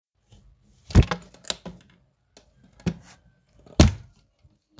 Sound Effects
Door Open Close 2
Door open close 2.m4a